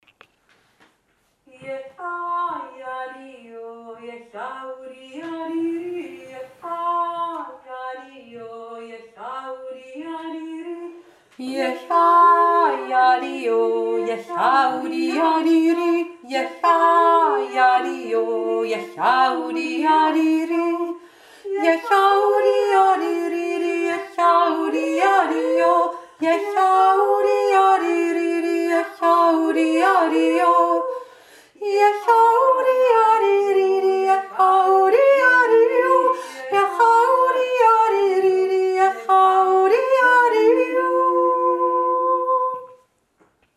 Hauptstimme
der-von-di-ondern-kanon.mp3